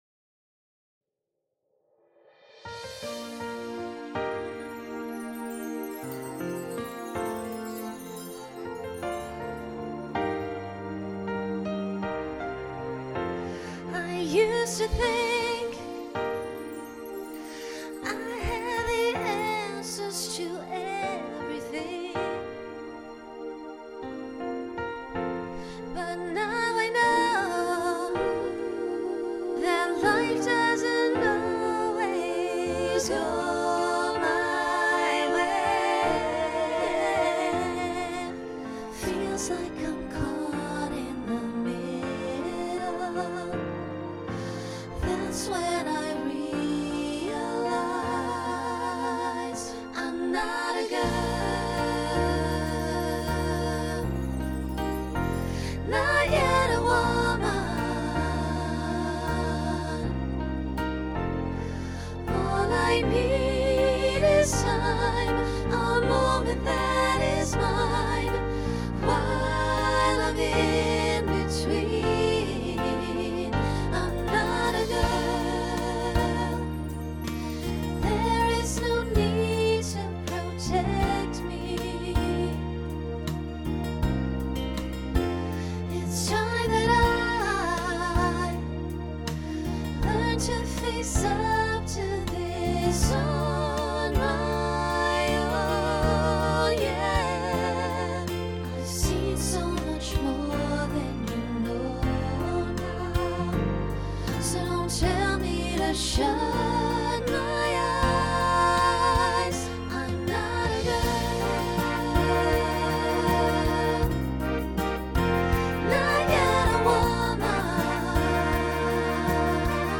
Genre Pop/Dance Instrumental combo
Function Ballad Voicing SSA